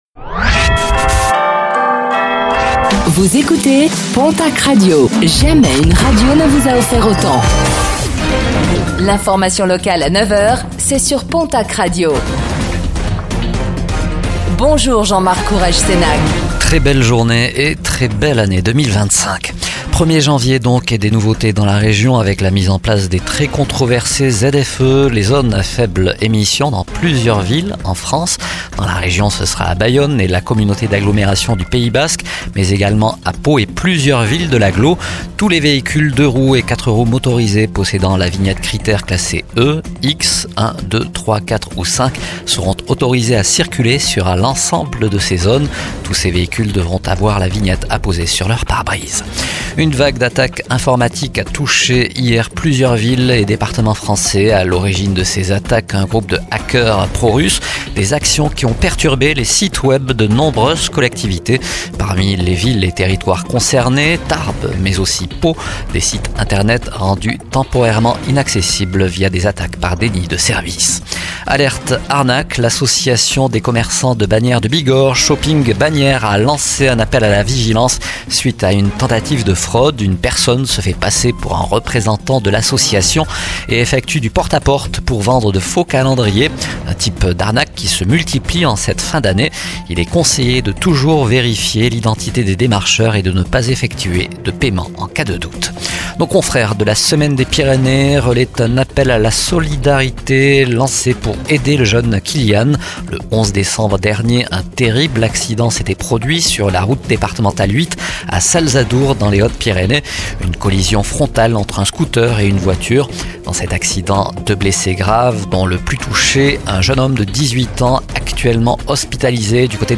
09:05 Écouter le podcast Télécharger le podcast Réécoutez le flash d'information locale de ce mercredi 1er janvier 2025